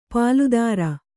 ♪ pāludāra